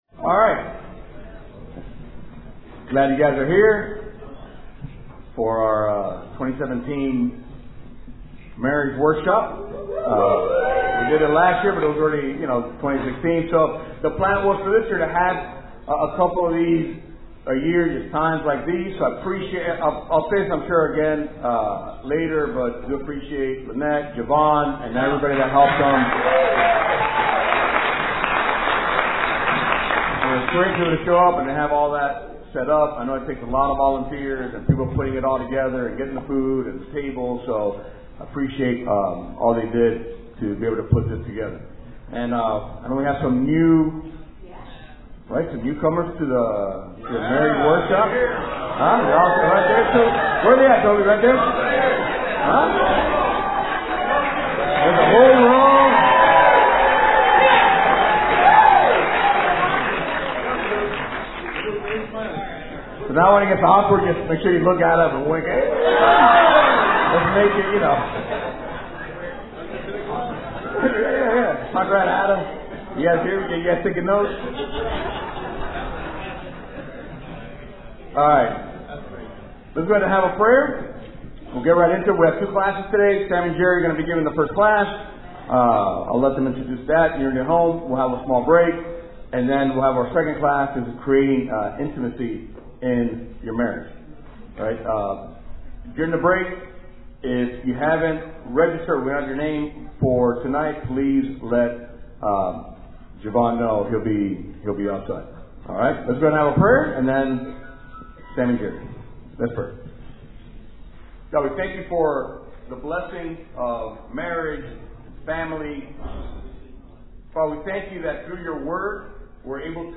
Palm+Beach+Marriage+Workshop+Class+01.mp3